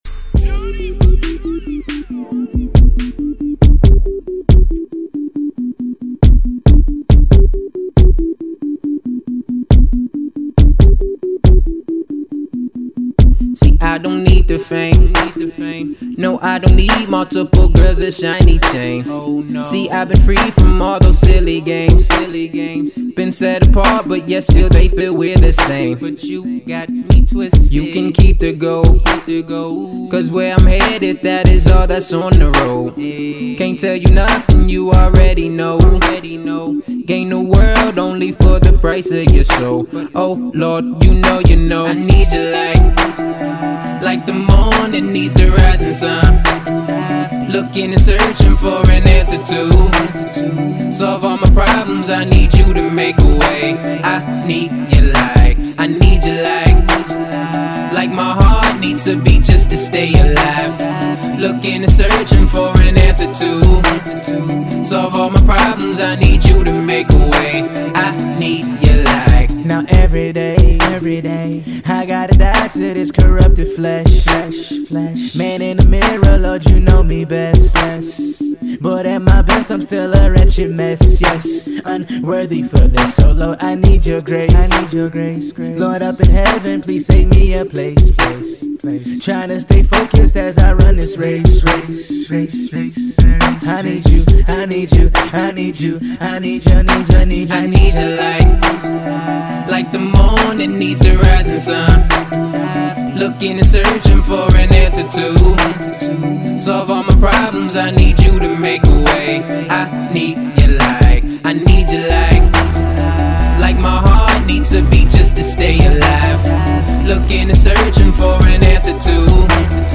rap gospel.